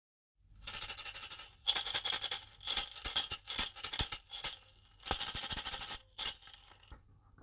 ダンゴマラカス 大
持ち手も太くなり、中身も小豆が入りサウンドもさらにグレードアップしました。高齢の方やお子様にも持ちやすく、小豆の切れの良いサウンドは、本格演奏からカラオケまで、広くお使いになれます。ダンゴマラカスは、現地ではマンボロと呼ばれ、串ダンゴ状のポピュラーなマラカス。
素材： 実 木 小豆